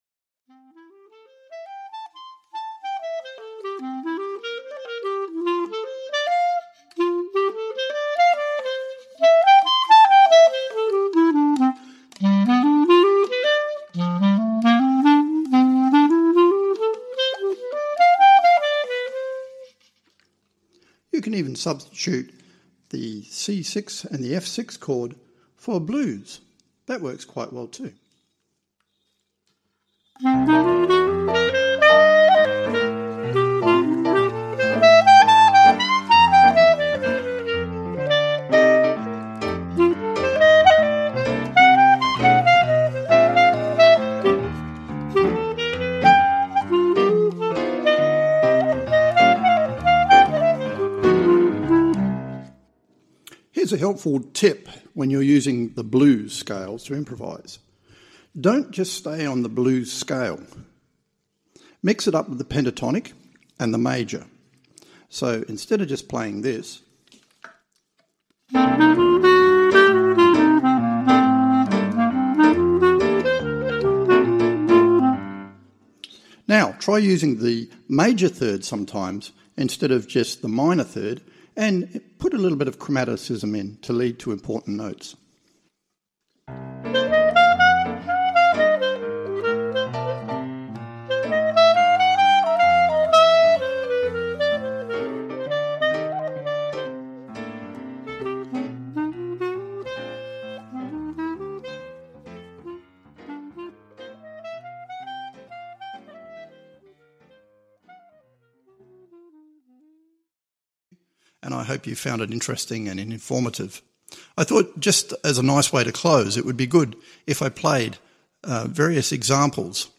A Sample from A Beginner’s Guide to Jazz Improvisation-Audiobook